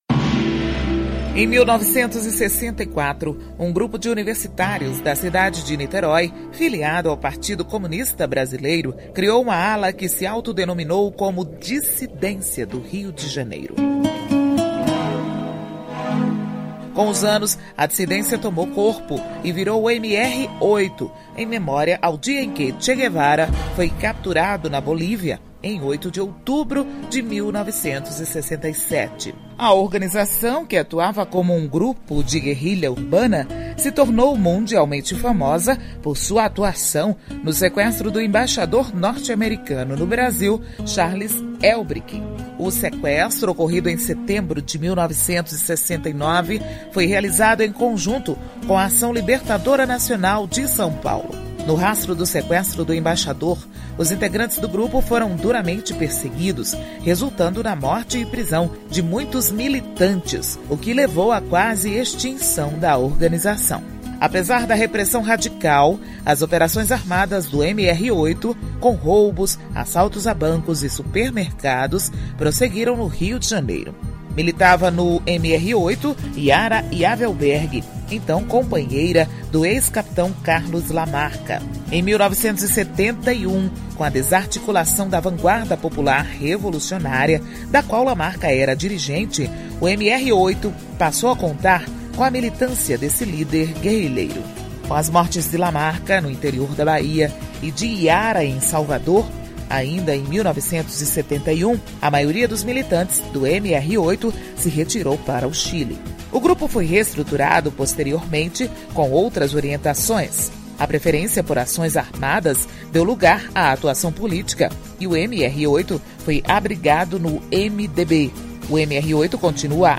História Hoje : Programete sobre fatos históricos relacionados às datas do calendário.